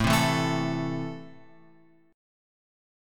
Am chord {5 3 2 2 x 5} chord
A-Minor-A-5,3,2,2,x,5.m4a